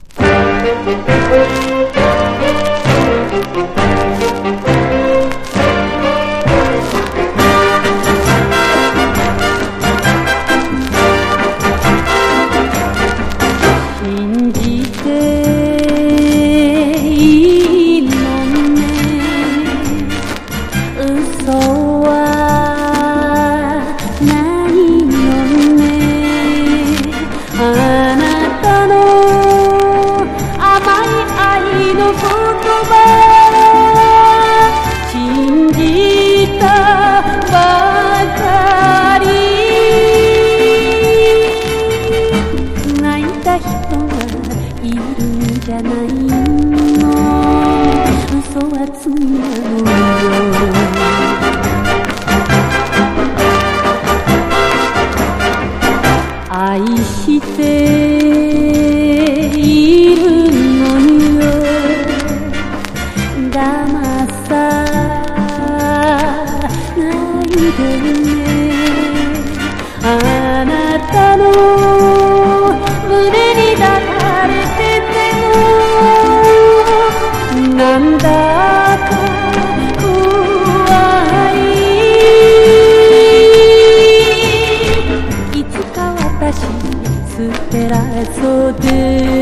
哀愁漂う歌謡曲